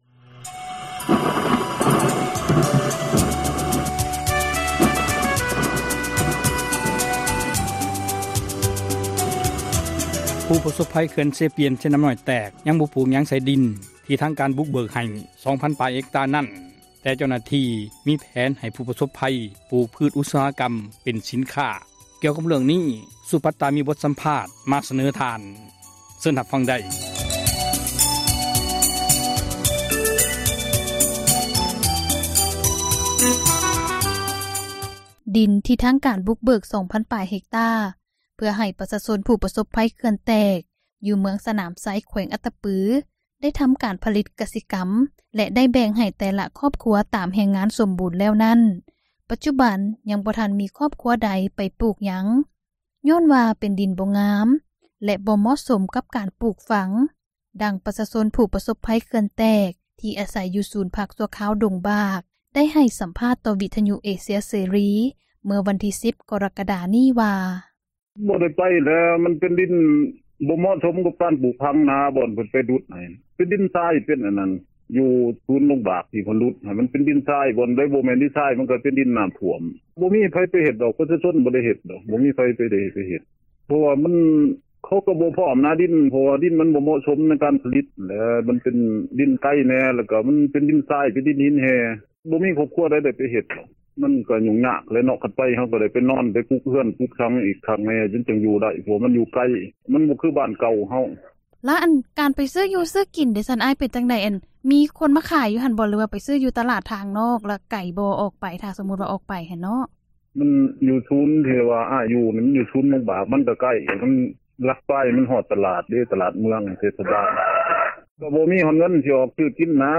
ດິນ ທີ່ທາງການ ບຸກເບີກ 2 ພັນ ປາຍເຮັກຕາ ເພື່ອ ໃຫ້ປະຊາຊົນ ຜູ້ປະສົພພັຍ ເຂື່ອນແຕກ ຢູ່ເມືອງສະໜາມໄຊ ແຂວງອັດຕະປື ໄດ້ທໍາການຜລິດ ກະສິກັມ ແລະ ໄດ້ແບ່ງໃຫ້ ແຕ່ລະຄອບຄົວ ຕາມແຮງງານ ສົມບູນ ນັ້ນ, ປັດຈຸບັນ ຍັງບໍ່ທັນມີ ຄອບຄົວໃດ ໄປ ປູກຝັງຫຍັງ ຍ້ອນວ່າ ເປັນດິນບໍ່ງາມ ແລະ ບໍ່ເໝາະສົມ ກັບການປູກຝັງ, ດັ່ງ ປະຊາຊົນ ຜູ້ປະສົພພັຍ ເຂື່ອນແຕກ ທີ່ອາສັຍຢູ່ ສູນພັກຊົ່ວຄາວ ດົງບາກ ໄດ້ໃຫ້ສັມພາດ ຕໍ່ ວິທຍຸເອເຊັຽເສຣີ ເມື່ອວັນທີ່ 10 ກໍຣະກະດາ ນີ້ວ່າ: ເຊີນຟັງສຽງ ສໍາພາດໄດ້:
ໃນຂນະດຽວກັນ ປະຊາຊົນ ຜູ້ປະສົບພັຍເຂື່ອນແຕກ ອີກຄົນນຶ່ງ ກໍກ່າວຕໍ່ ວິທຍຸເອເຊັຽເສຣີວ່າ ດິນ ທີ່ທາງການບຸກເບີກ ໃຫ້ປະຊາຊົນ ທໍາການຜລິດກະສິກັມນັ້ນ ຢູ່ໄກ ແລະ ເປັນດິນບໍ່ງາມ ຈຶ່ງບໍ່ມີຄອບຄົວໃດ ໄປປູກຫຍັງໃສ່ເທື່ອ, ເຊີນທ່ານຟັງສຽງ ສໍາພາດ ຕໍ່ໄປໄດ້ ...